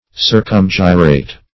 Search Result for " circumgyrate" : The Collaborative International Dictionary of English v.0.48: Circumgyrate \Cir`cum*gy"rate\, v. t. & i. [Pref. circum- + gyrate.]